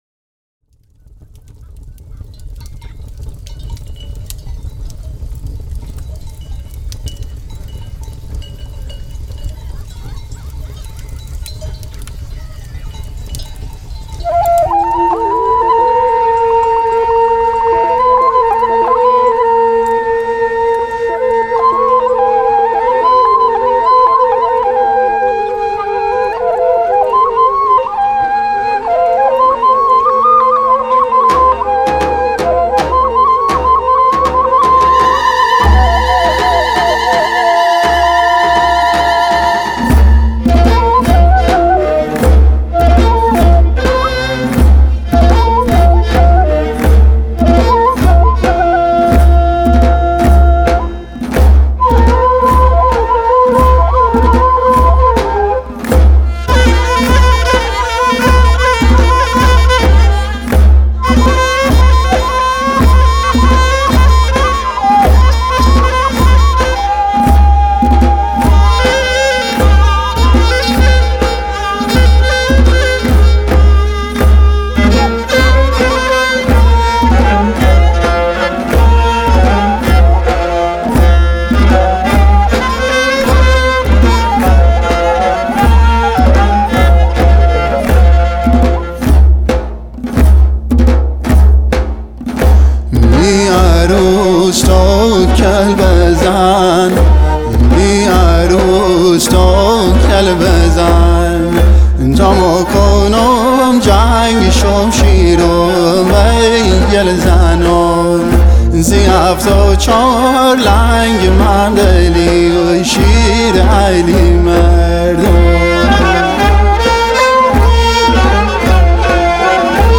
آهنگ مقامی لُری
کمانچه
نی لَبی هفت بند
کرنا